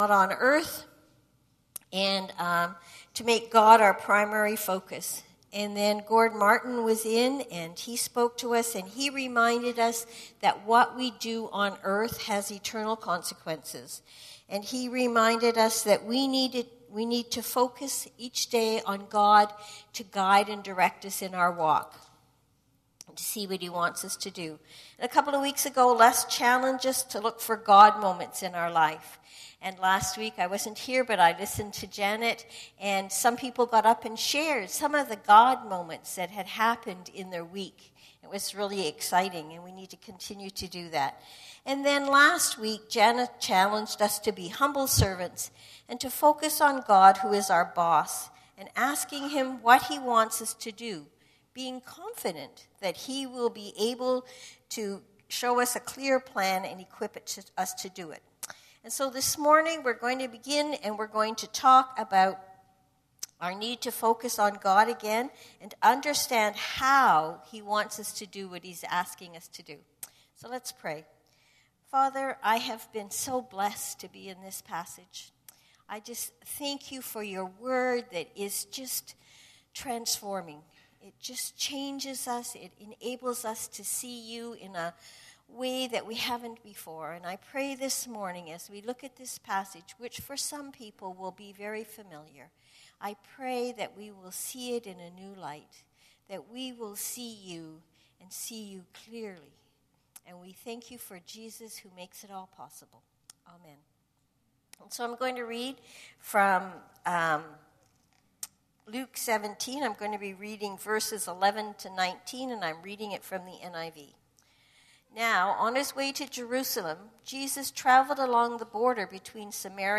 This sermon is based on Luke 17:11-19.